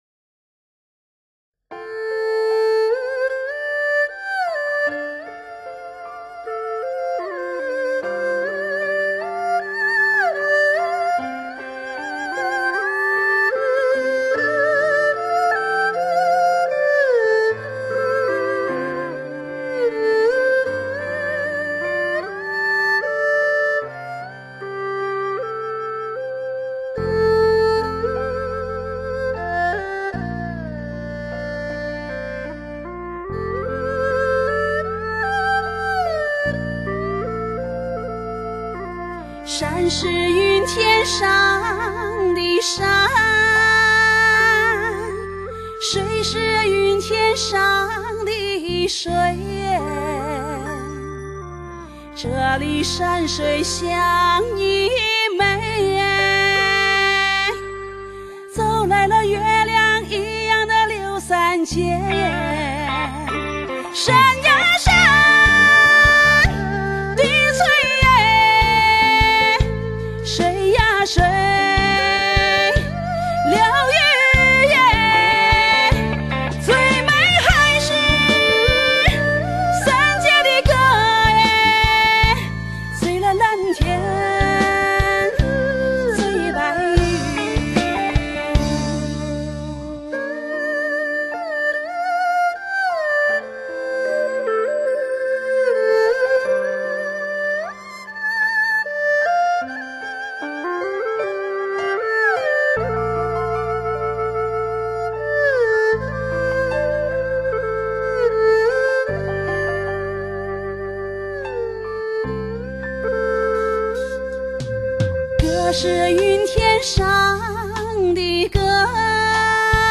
首推“山歌”主题发烧处女大碟
曲调多高亢，嘹亮，节奏多自由，悠长。
在高音区，山歌还常常会有自由延长音，有的山歌因音域较高而使用假声或真假声结合的歌唱方法。